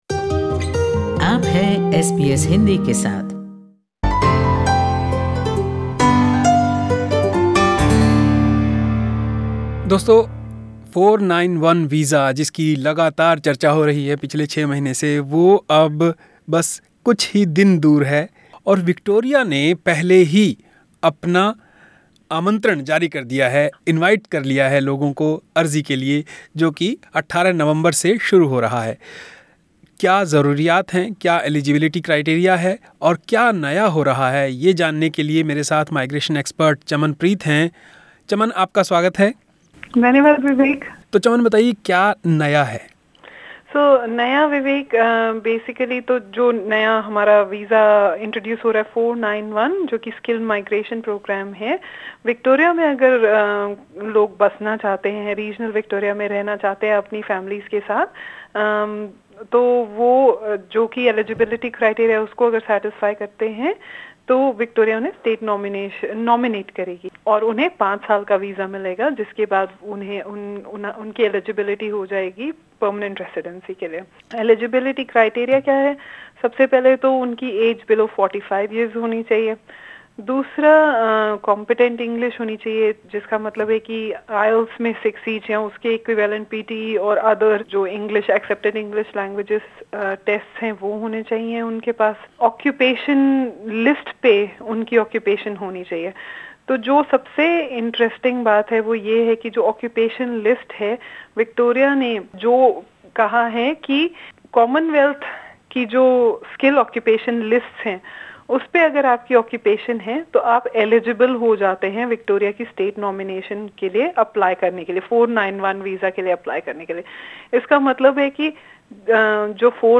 Listen to this report and find out, what are the requirements to apply.